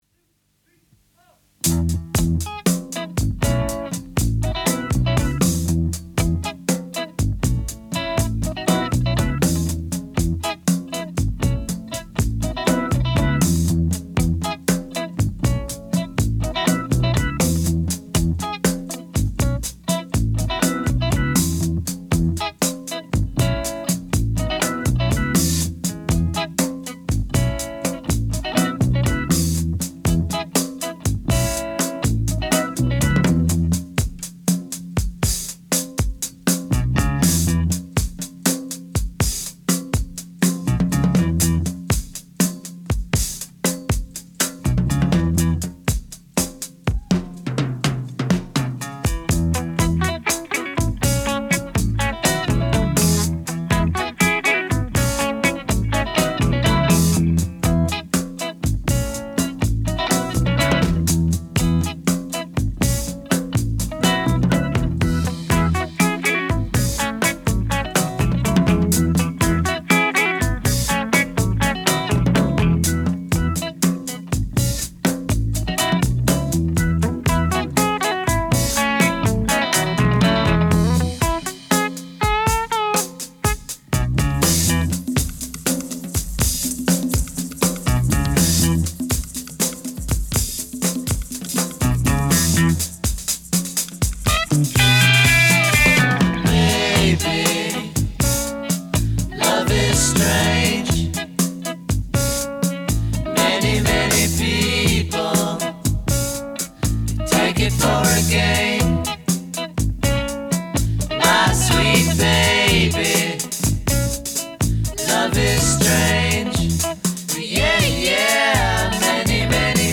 R&B